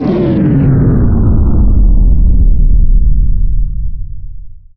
SCIFI_Down_12_mono.wav